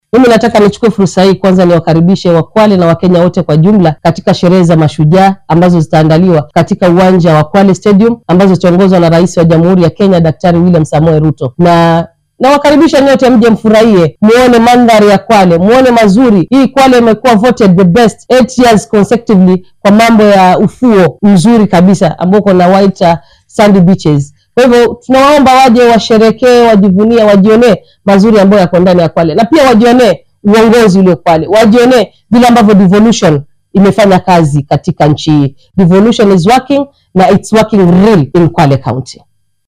Barasaabka ismaamulka Kwale , Fatuma Maxamad Achani ayaa dhankeeda xustay in ay kenyaanka kale ku soo dhaweynayaan dowlad deegaankaasi si ay goobjoog uga noqdaan horumarrada uu horseeday hannaanka dowlad wadaajinta.